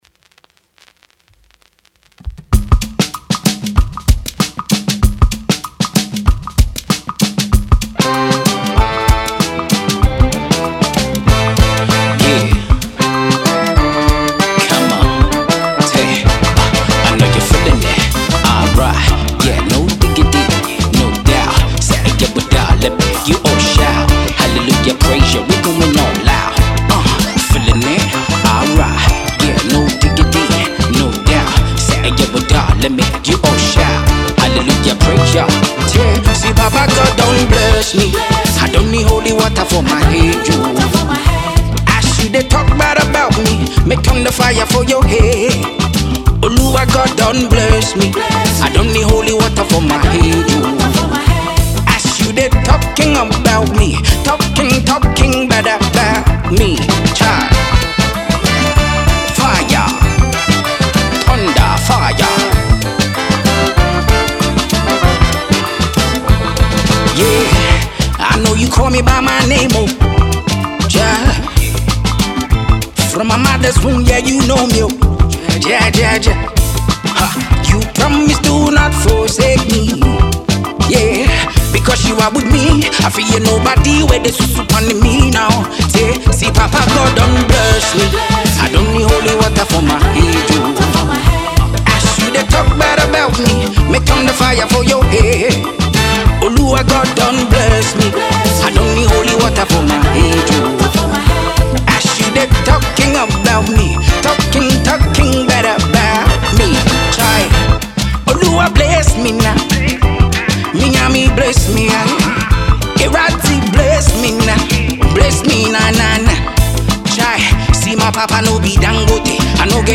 Afro Gospel jam
portrays full characteristics of an Afrobeat song